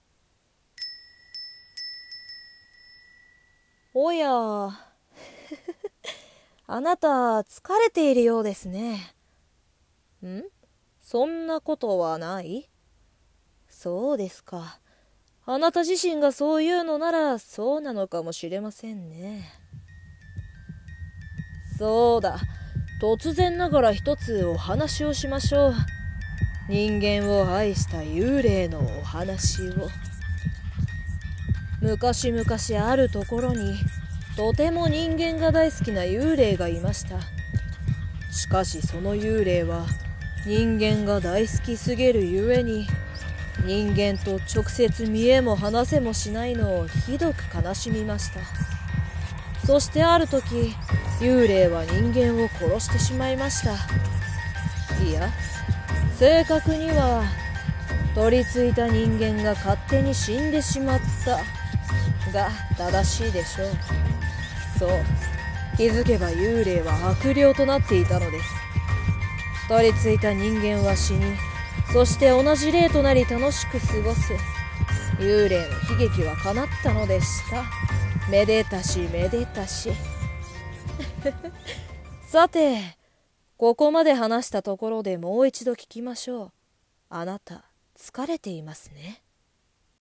【声劇】あなた、つかれていますね？